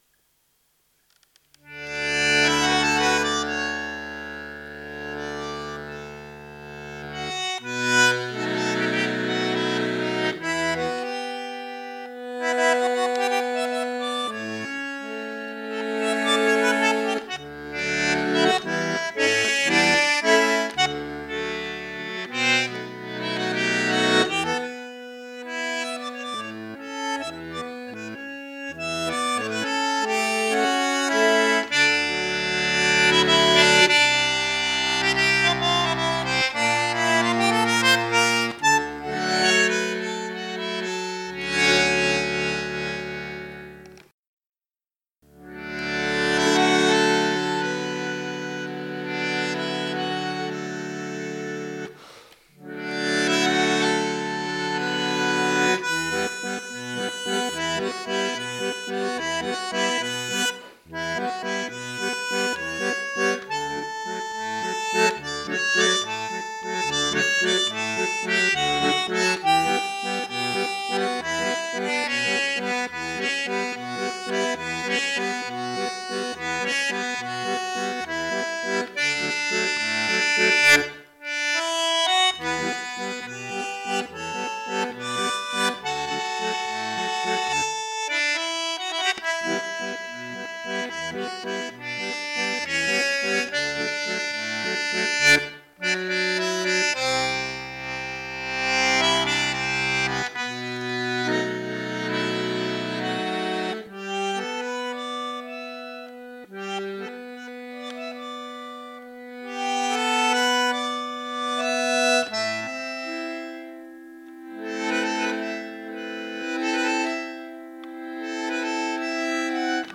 Bandonion * neón - Kranwerk - Naunhof
Probemitschnitt gespielt auf AA II/II 144tönig oktav Bj. 1936: